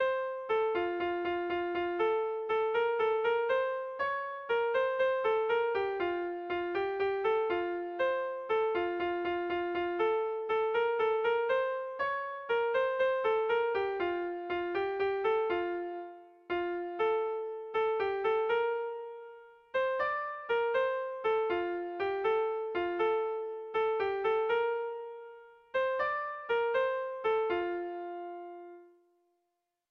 Dantzakoa
ABAB